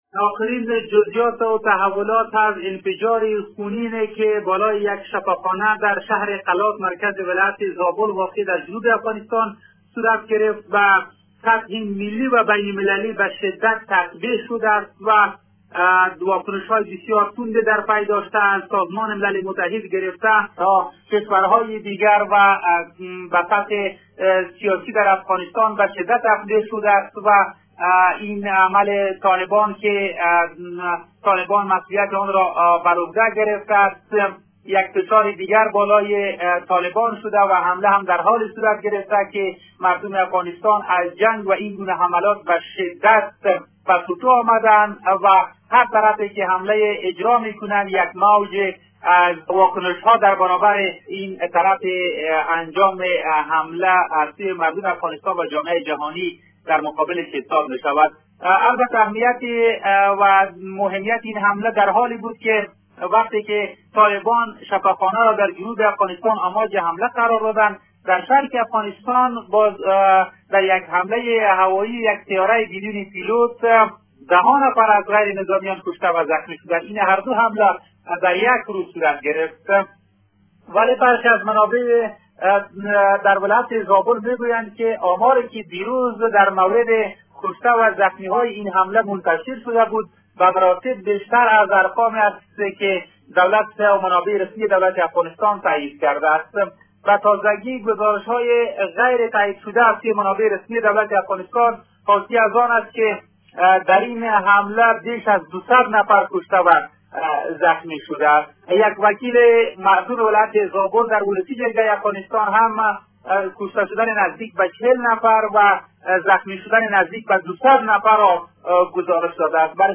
جزئیات بیشتر در گزارش خبرنگار رادیودری: